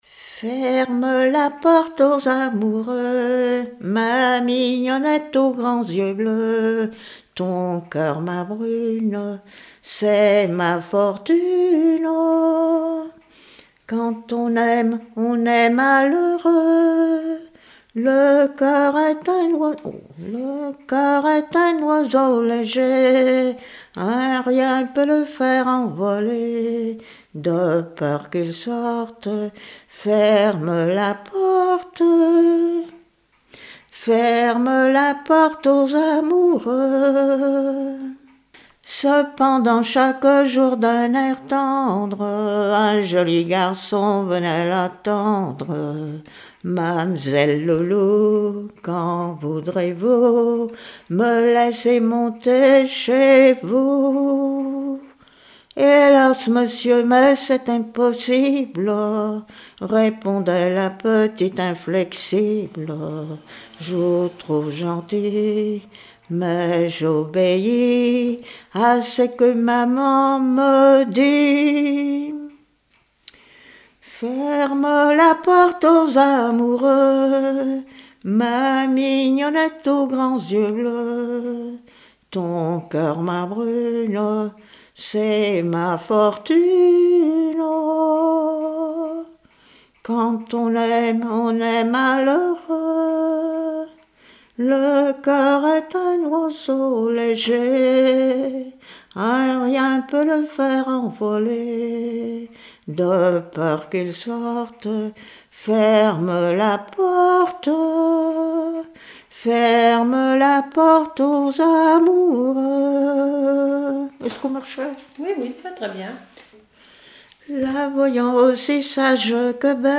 Genre strophique
Témoignages, chansons de variété et traditionnelles
Pièce musicale inédite